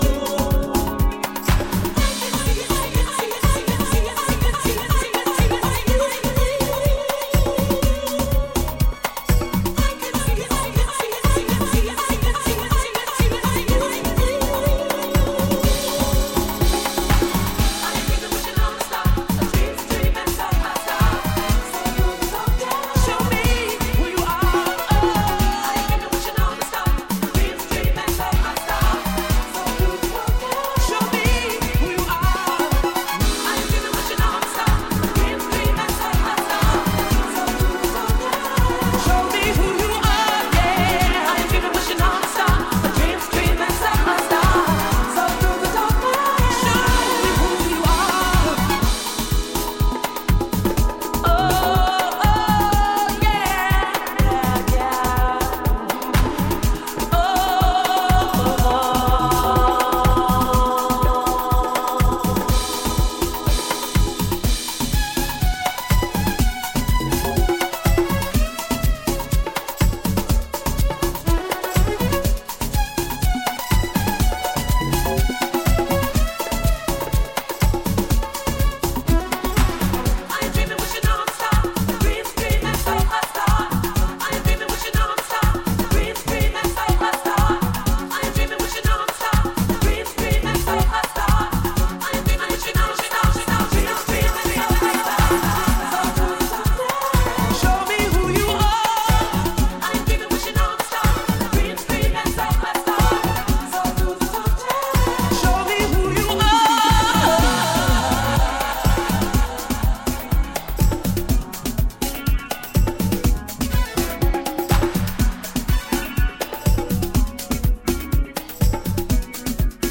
B面のDubももちろん最高。